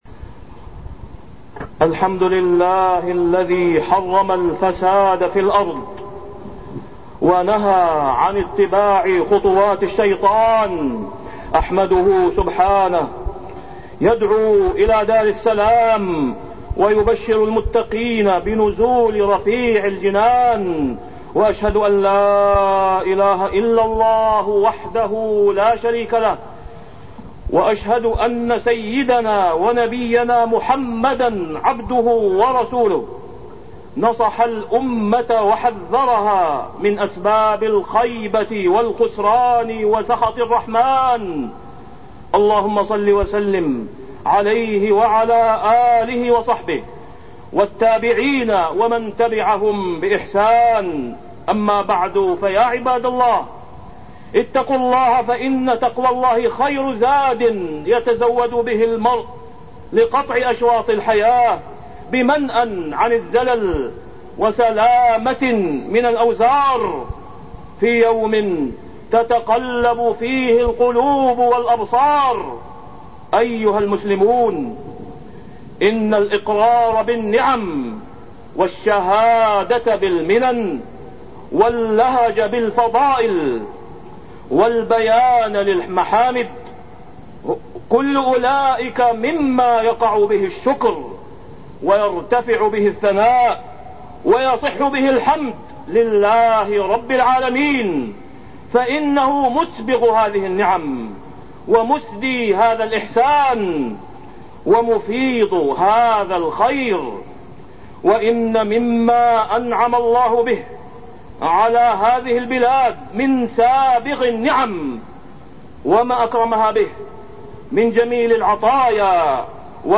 تاريخ النشر ٢ ربيع الثاني ١٤٢٤ هـ المكان: المسجد الحرام الشيخ: فضيلة الشيخ د. أسامة بن عبدالله خياط فضيلة الشيخ د. أسامة بن عبدالله خياط الفئة الضالة